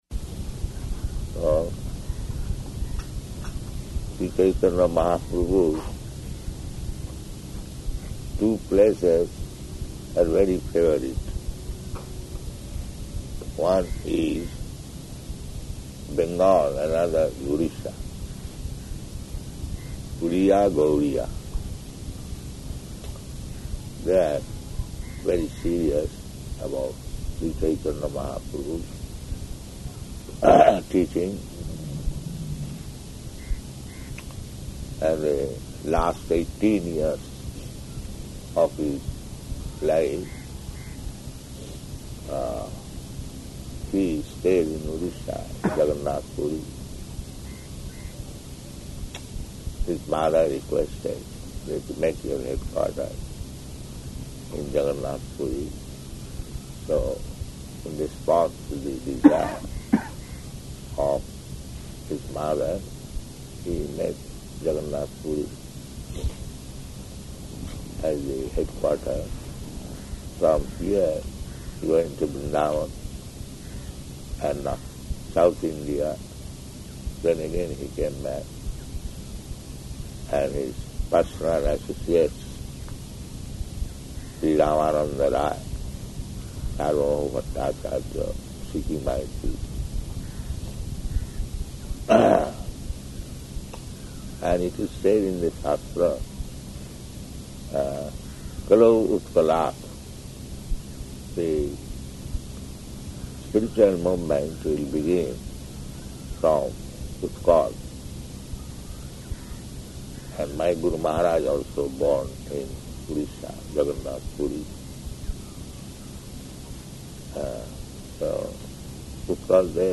Arrival [partially recorded]
Location: Bhubaneswar